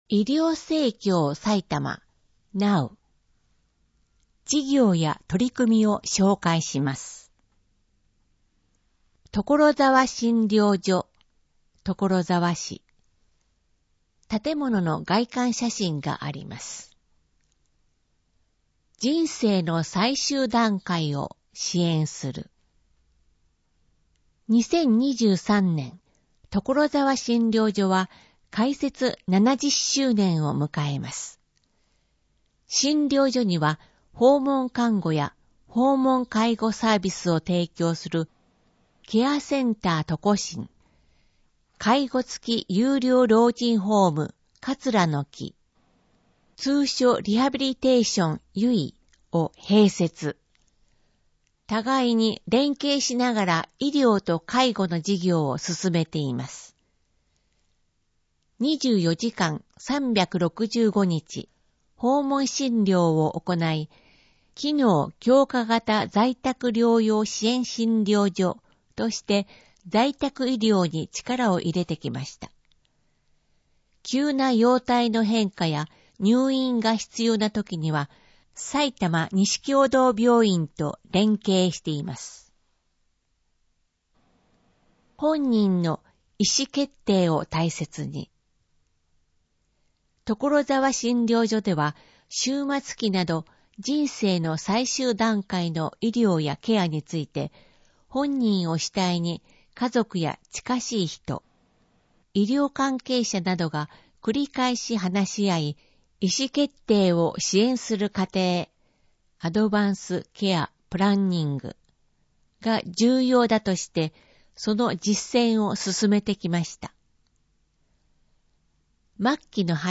2022年2月号（デイジー録音版）